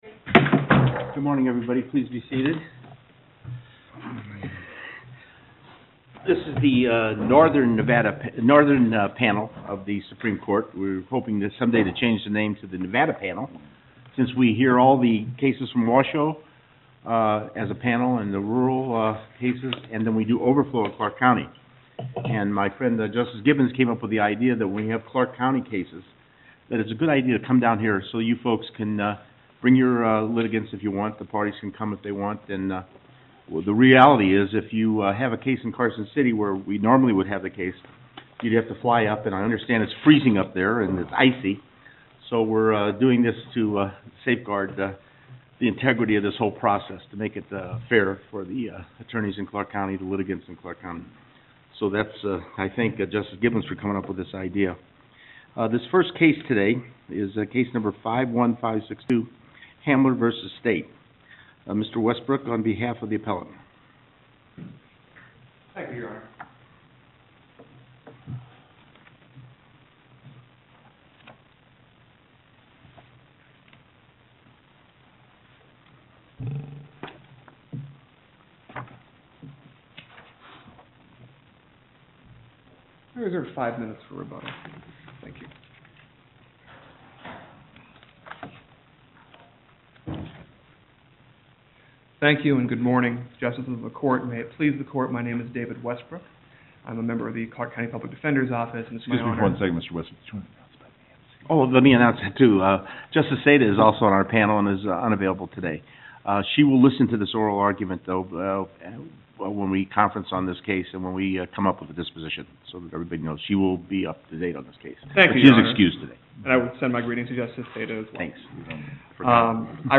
Loading the player Download Recording Docket Number(s): 51562 Date: 12/10/2009 Time: 10:00 AM Location: Las Vegas Before the NNP09: Cherry/Saitta/Gibbons, JJ.